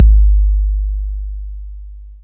DB - Kick (8).wav